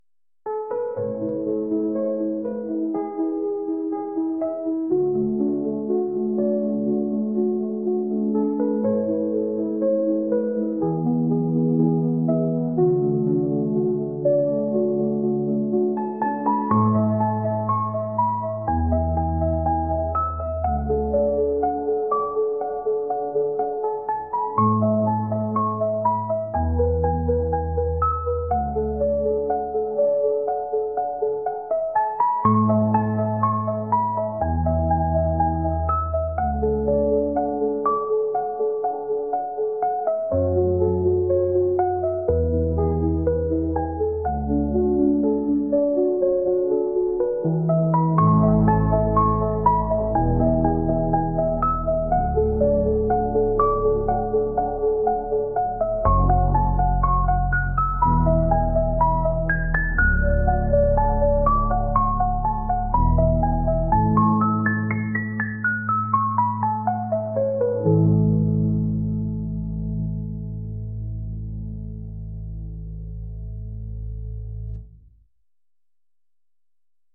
「癒し、リラックス」